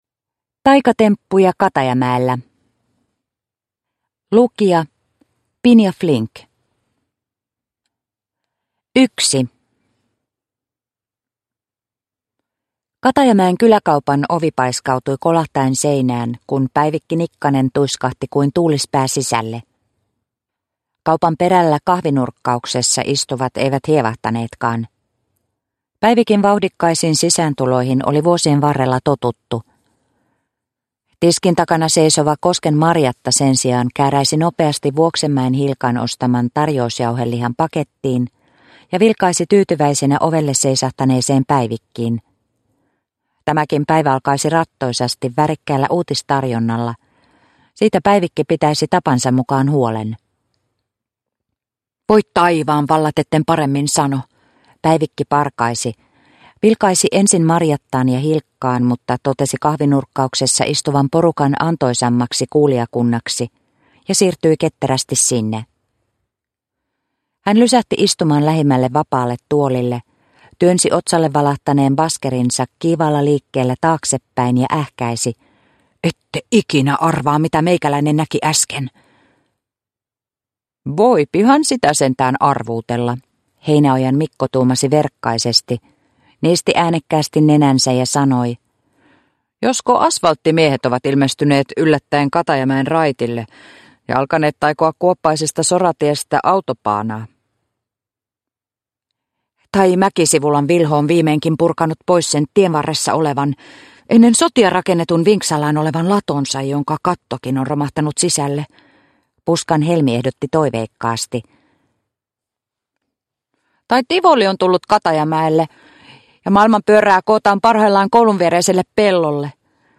Taikatemppuja Katajamäellä – Ljudbok – Laddas ner